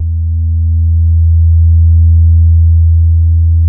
XP SUBASE A1.wav